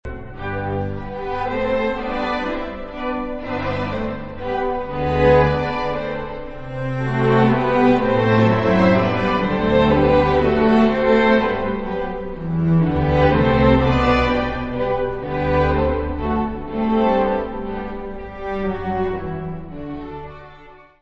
Notes:  Gravado no Concert Hall, New Broadcasting House, Manchester, de 26 a 27 de Outubro, 1993; Disponível na Biblioteca Municipal Orlando Ribeiro - Serviço de Fonoteca
Music Category/Genre:  Classical Music
Andantino grazioso.